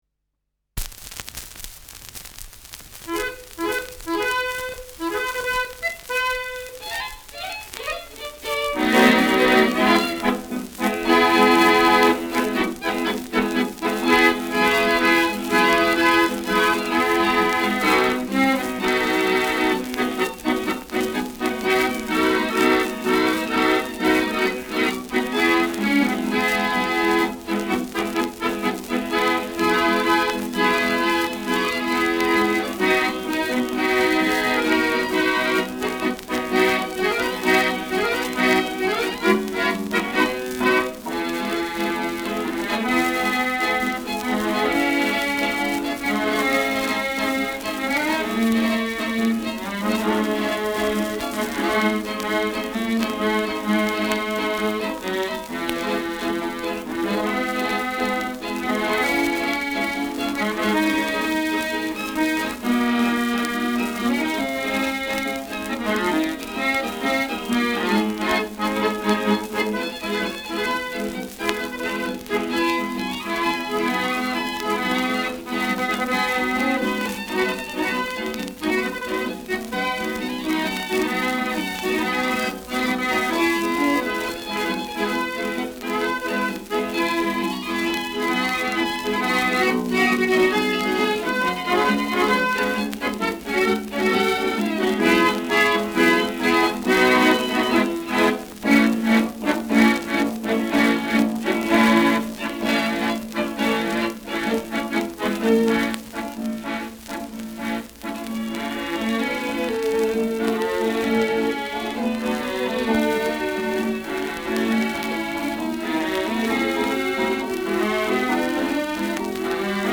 Schellackplatte
Tonrille: leichter Abrieb
leichtes Knistern